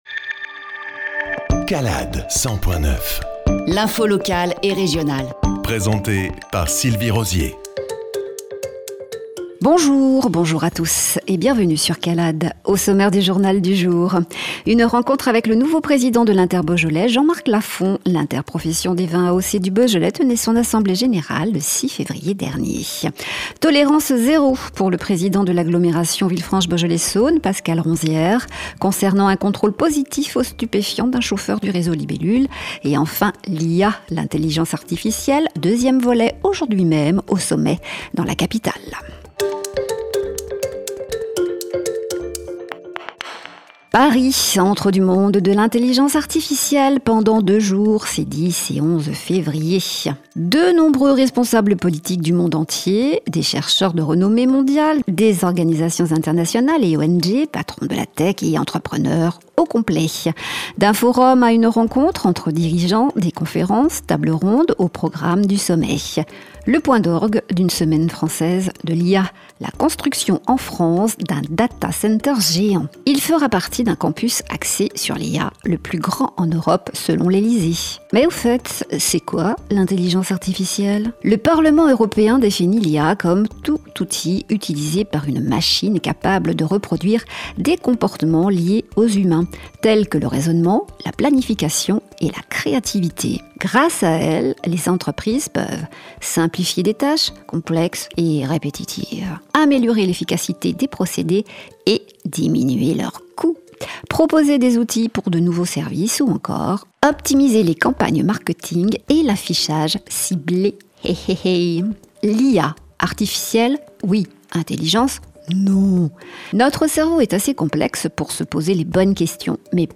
JOURNAL du 11-02-25